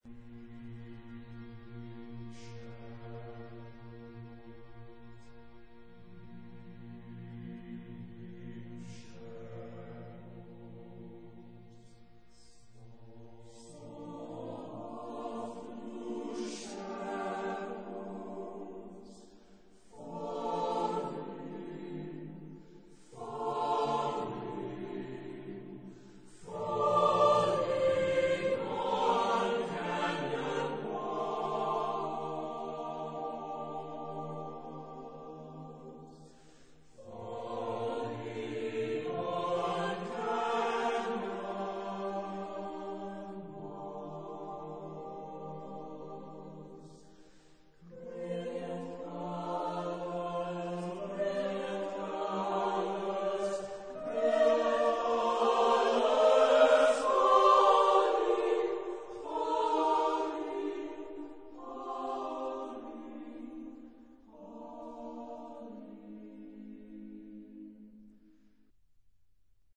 Genre-Style-Forme : Profane
Type de choeur : SSAATB  (6 voix mixtes )
Tonalité : si bémol majeur